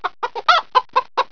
chicken.wav